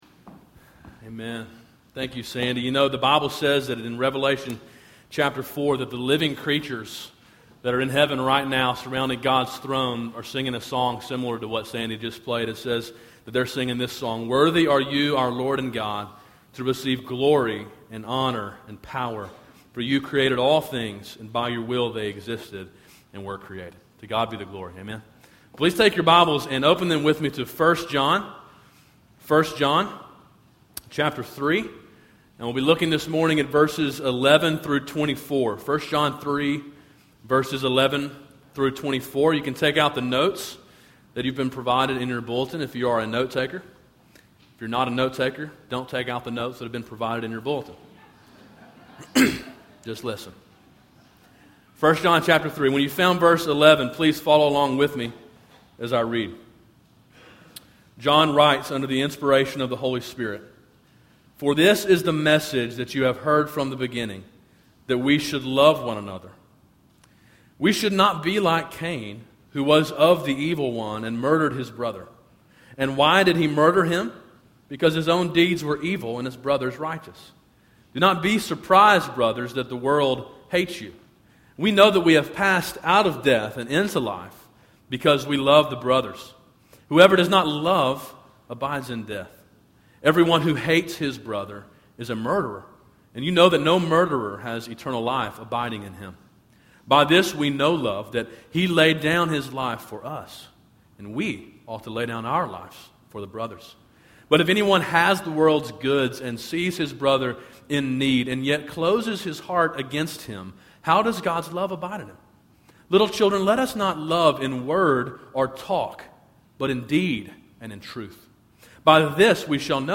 A sermon in a series on the book of 1 John titled Signs of Salvation.